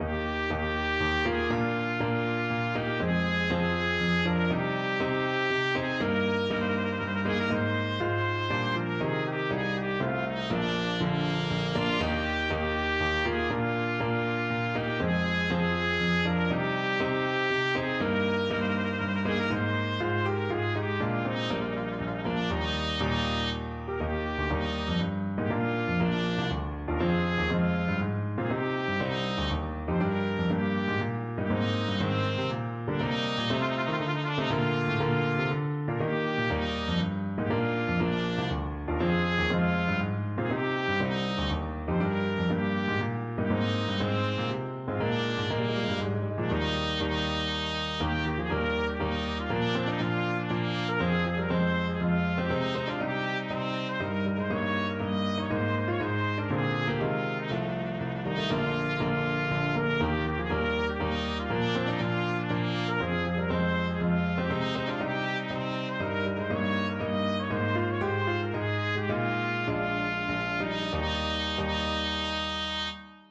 3/4 (View more 3/4 Music)
Bb4-Eb6
Sprightly = c. 120